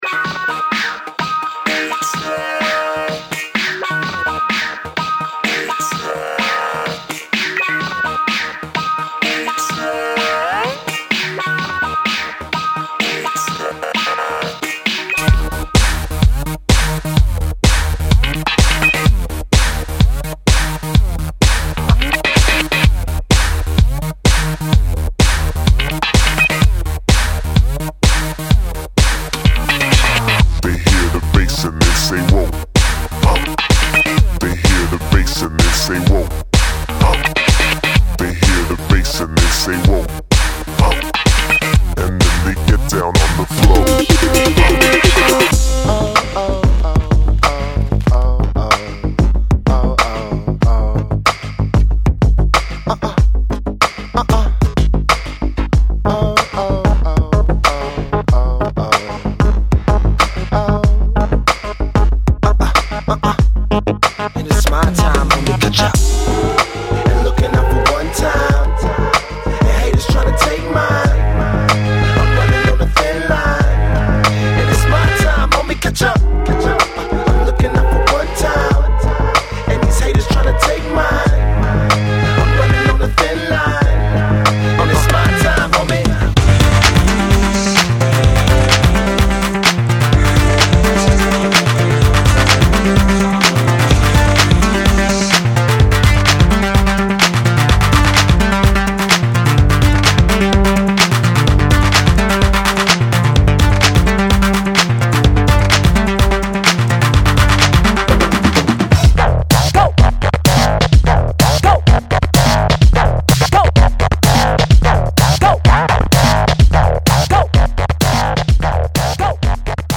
electro meets hip-hop album offers many memorable beats